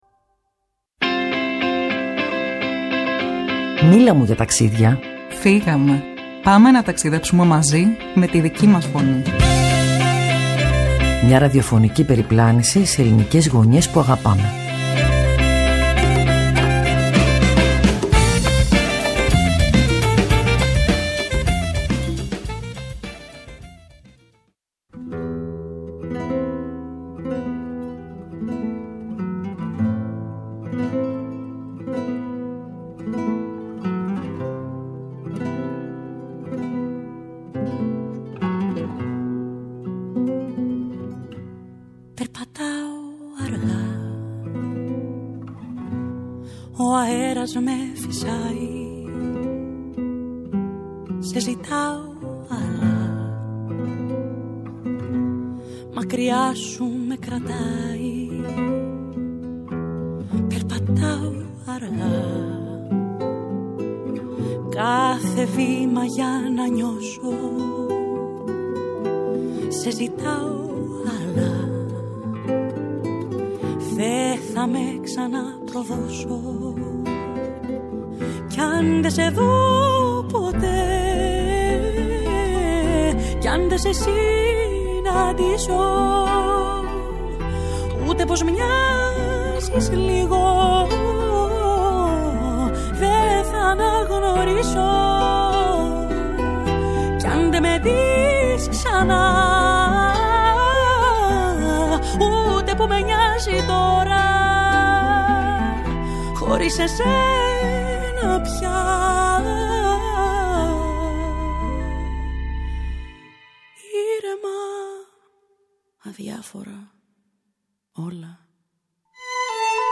Καλεσμένη της εκπομπής